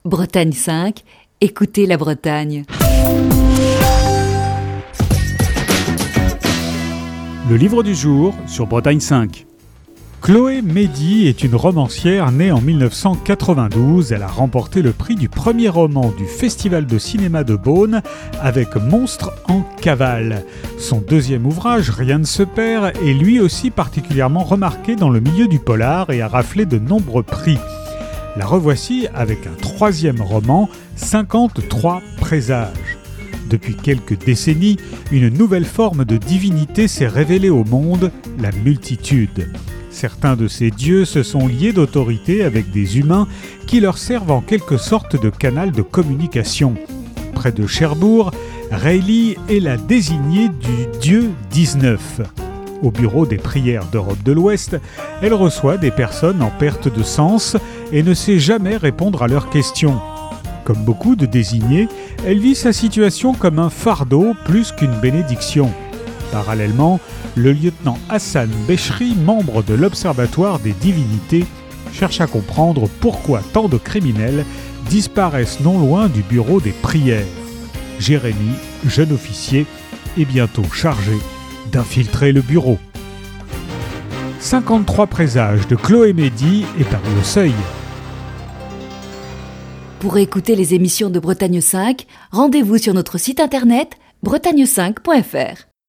Chronique du 26 avril 2021.